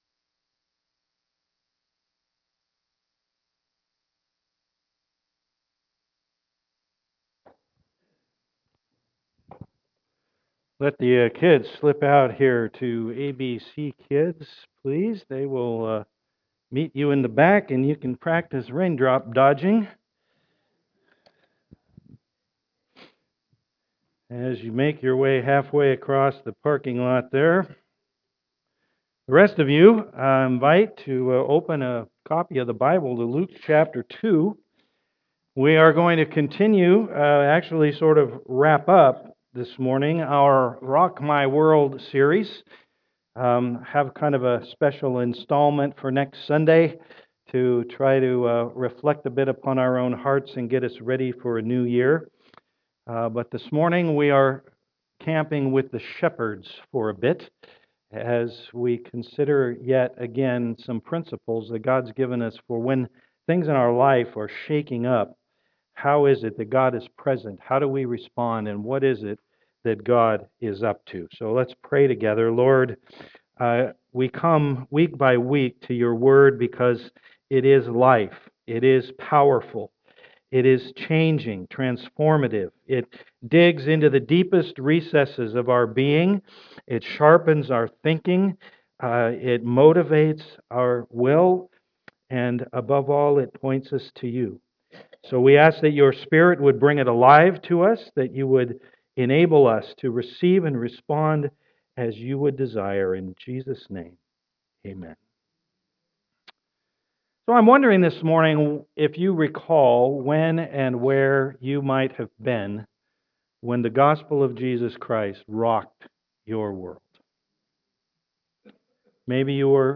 Passage: Luke 2:1-20 Service Type: am worship